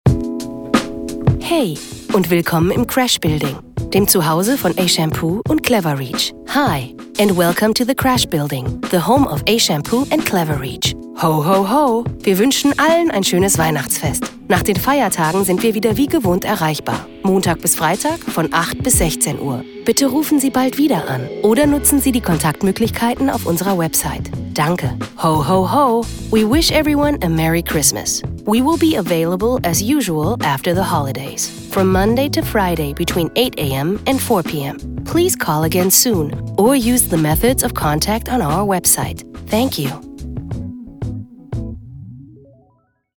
Ashampoo Ansage
Ashampoo_Telefonansage_DEMO.mp3